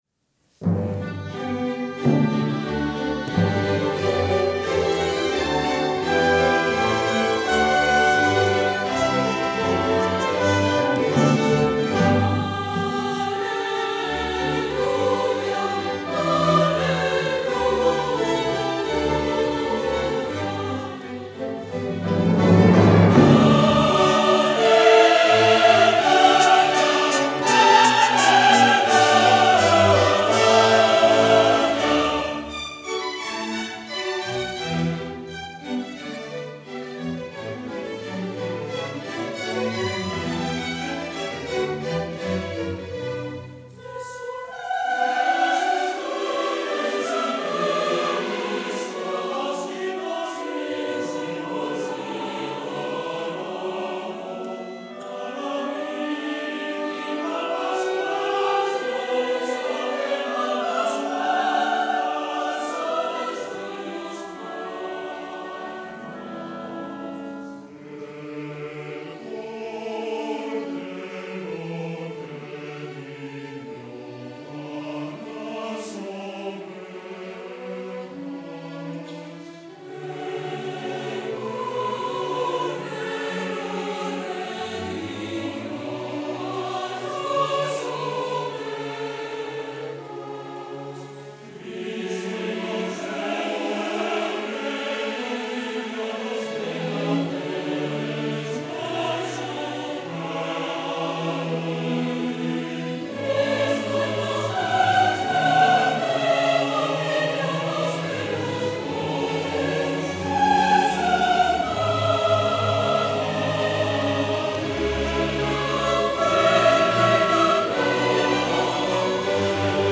Musikaste 1994 - Musikaste 50